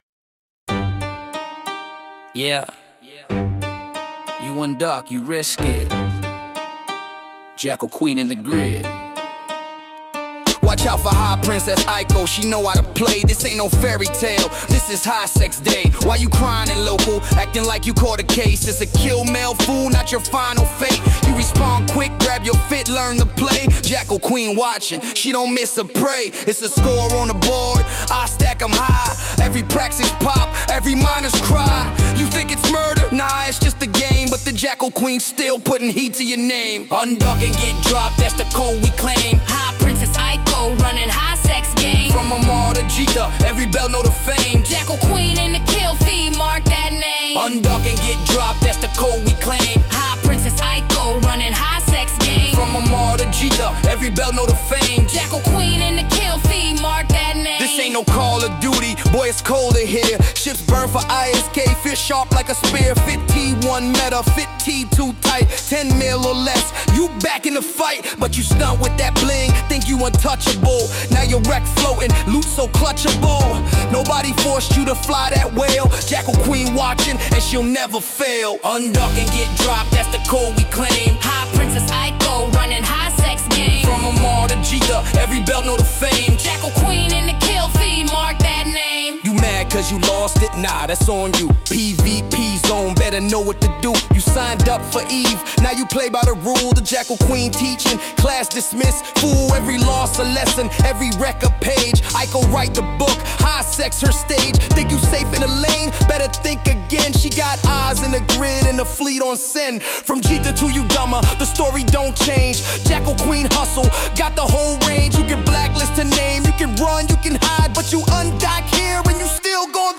Instead of debating with plebs, from now on I will be generating top tier space music in response to posts I agree with: